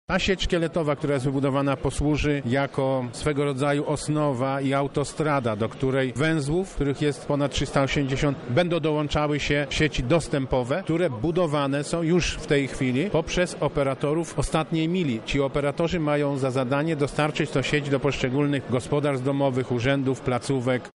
To przełomowe wydarzenie jeśli chodzi o cyfryzację Lubelszczyzny – mówi marszałek województwa Sławomir Sosnowski.